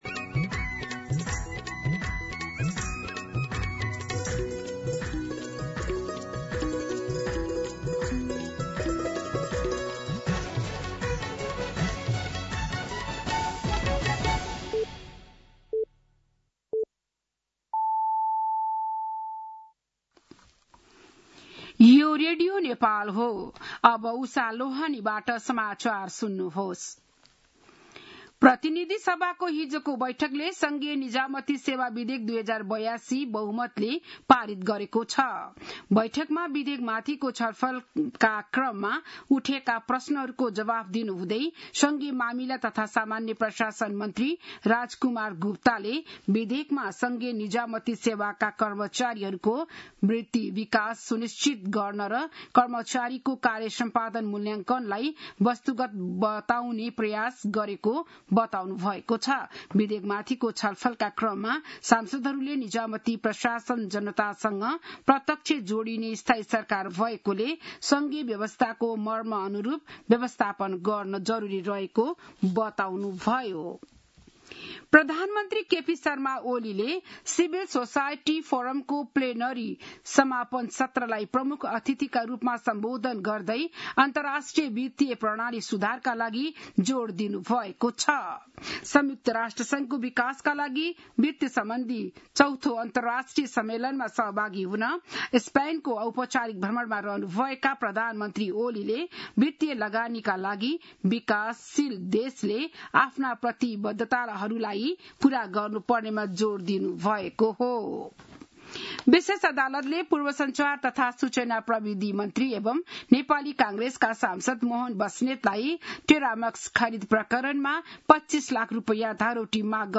बिहान ११ बजेको नेपाली समाचार : १६ असार , २०८२